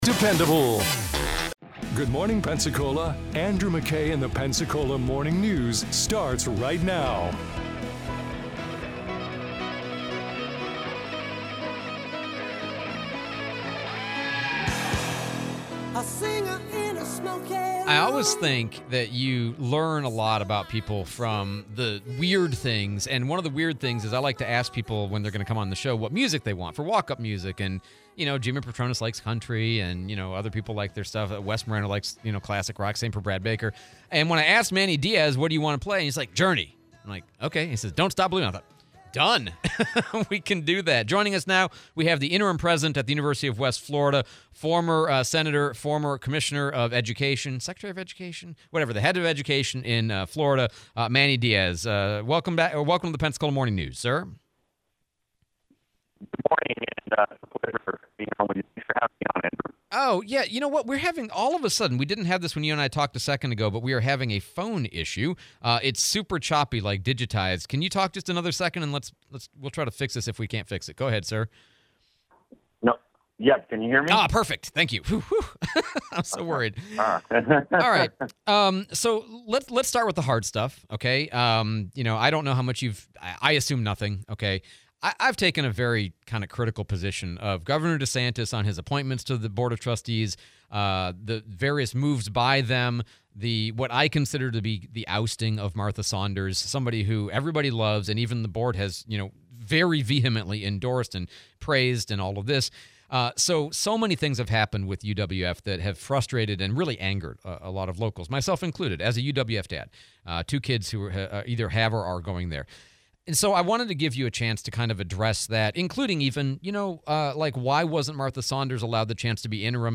07/16/25 UWF Manny Diaz Interview